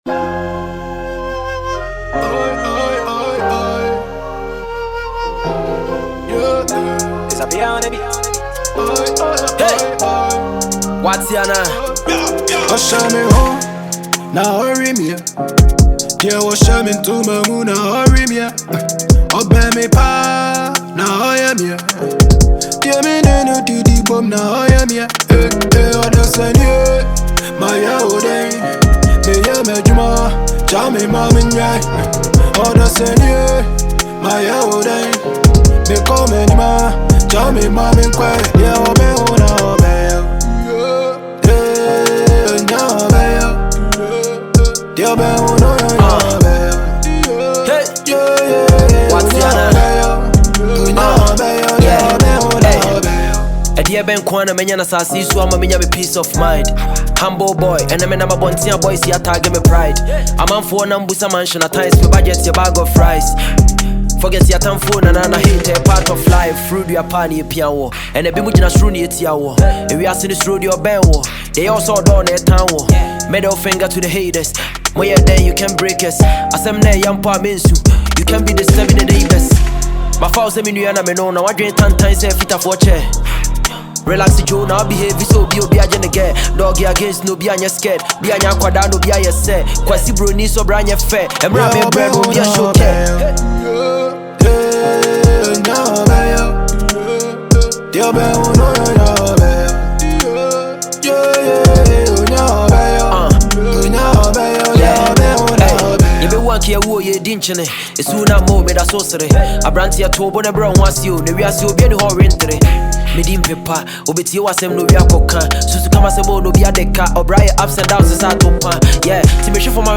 Ghana Music Music
Ghanaian producer